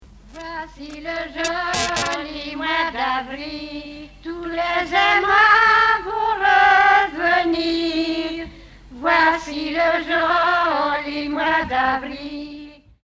Origine : Bretagne (pays de Redon) Année de l'arrangement : 2015
cassette audio